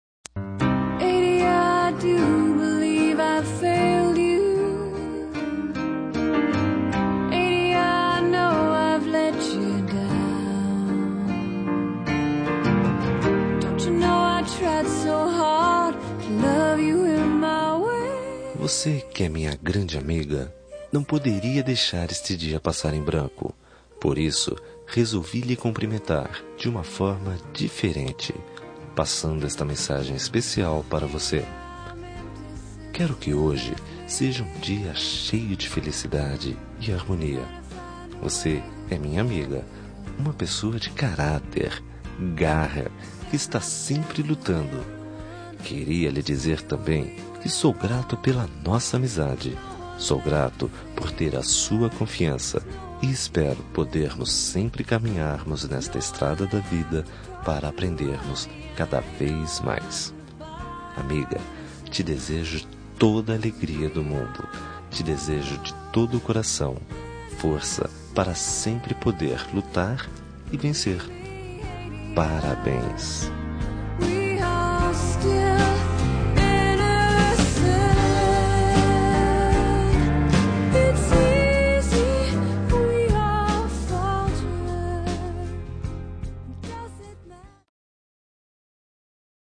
Telemensagem Aniversário de Amiga – Voz Masculina – Cód: 1581